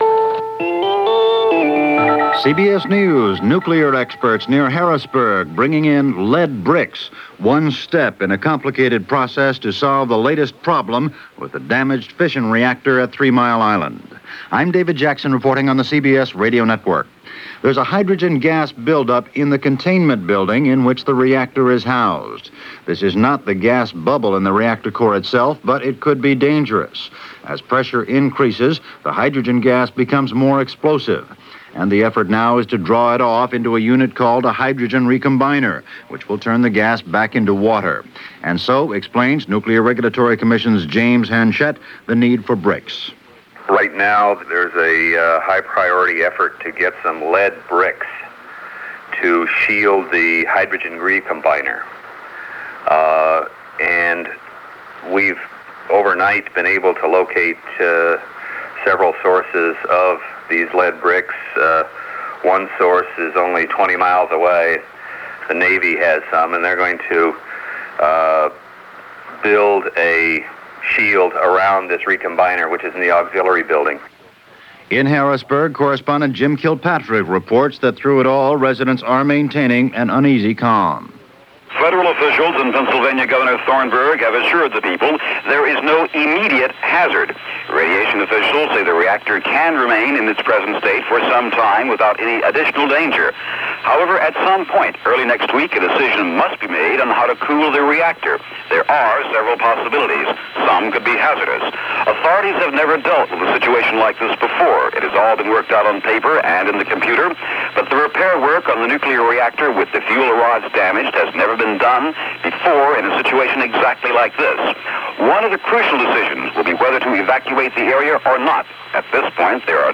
Continuous news reports – CBS Radio News